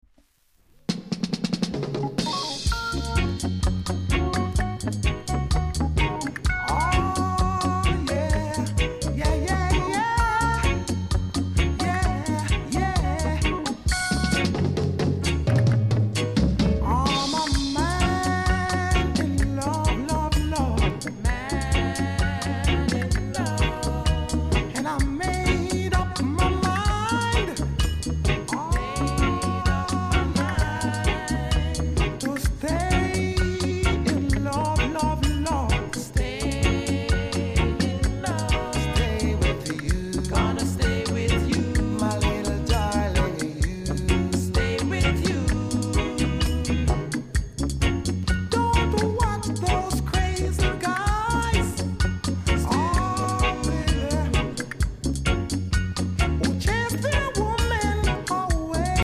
コメント 渋いROOTS ROCK REGGAE!!